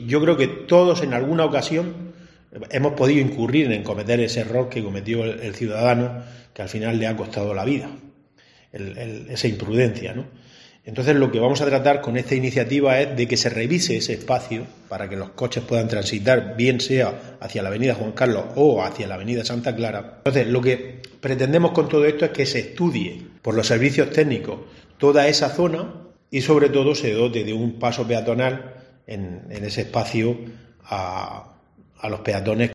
Pedro Sosa, portavoz de IU Podemos en Lorca